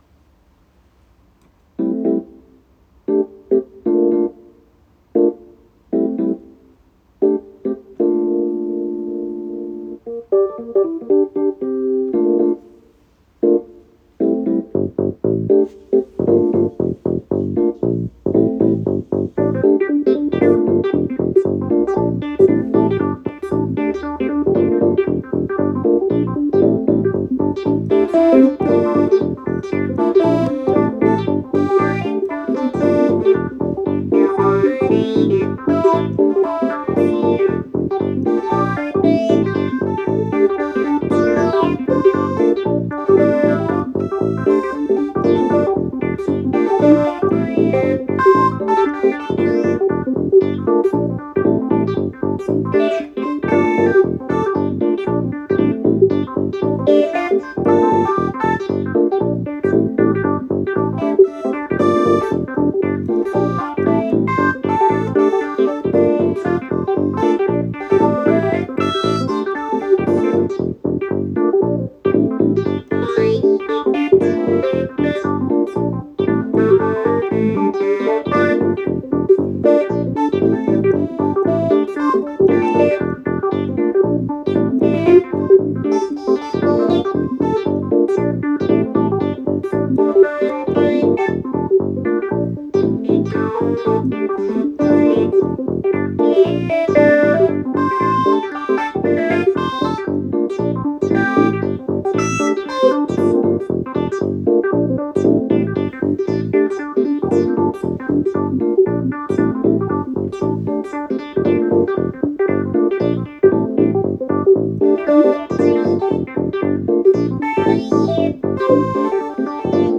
阅读歪歪（MeganeY君=阿盐）原创小说《音毒》前八章后，脑内loop出了这样一段场景bgm。